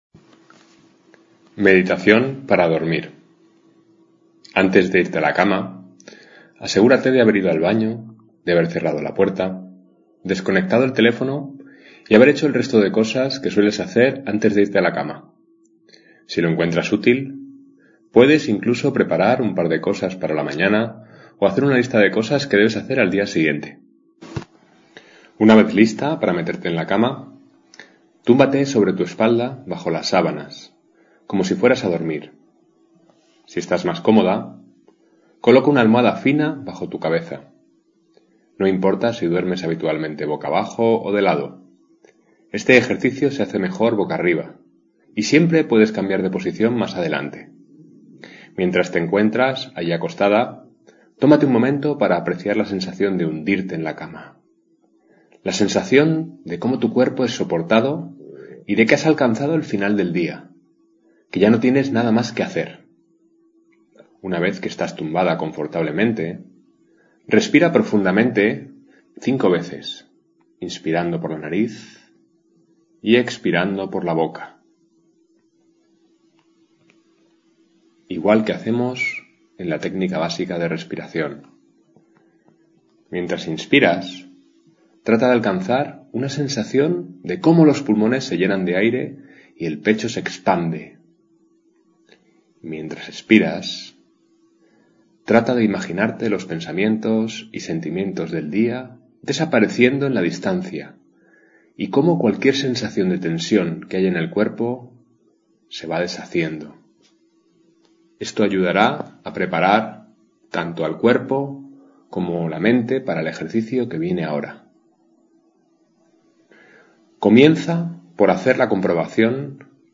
meditacionparadormir2.mp3